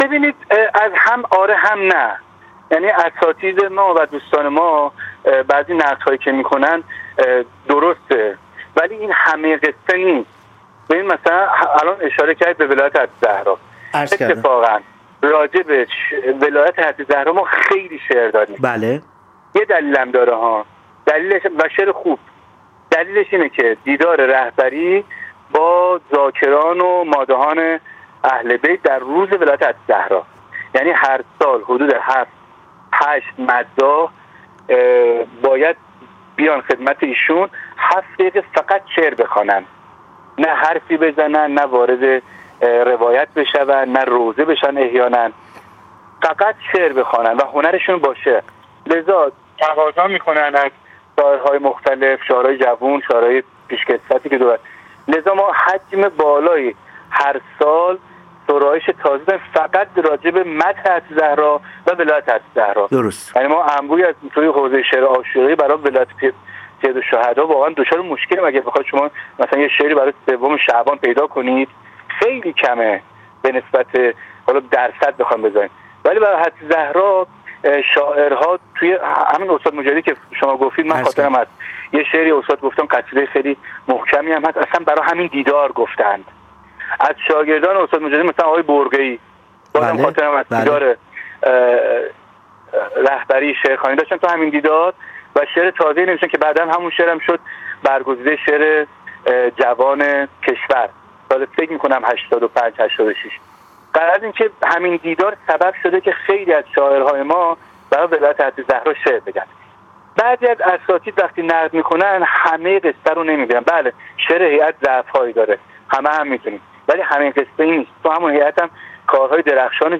گفت‌وگو با ایکنا